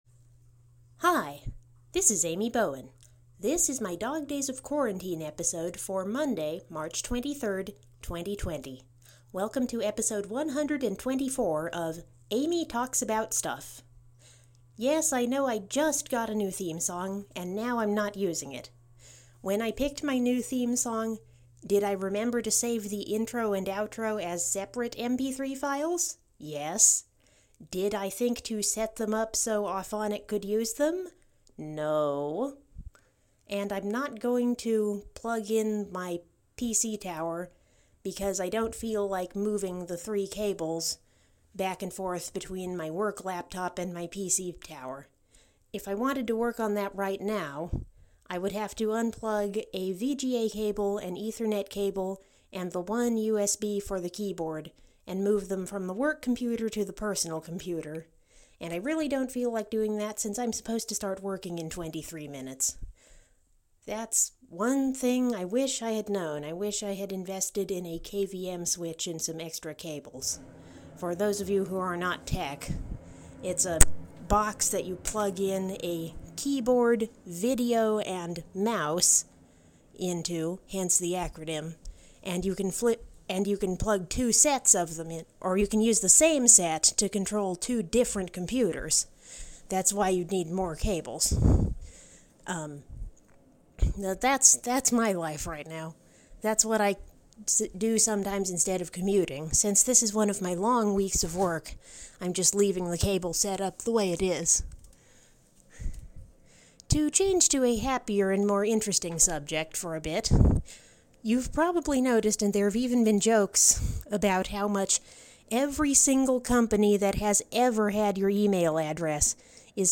My first 100% phone-made episode since summer. No theme music in this one. I talk about a new challenge I just read about today.